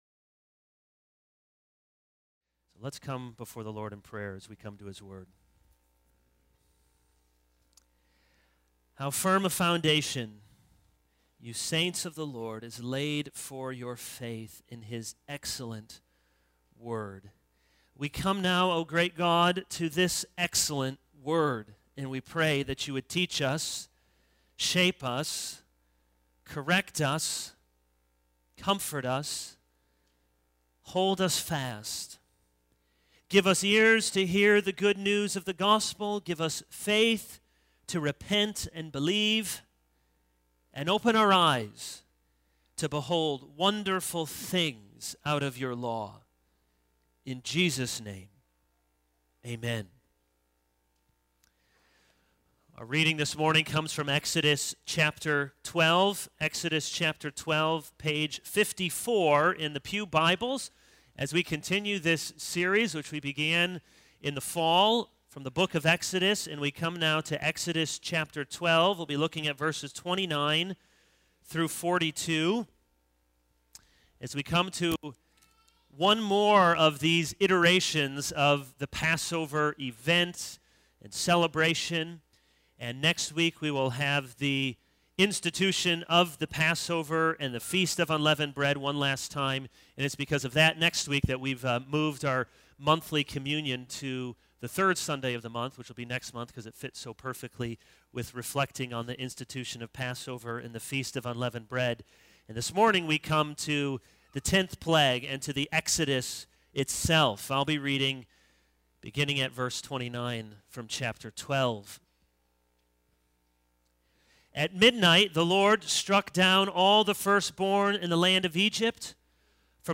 This is a sermon on Exodus 12:29-42.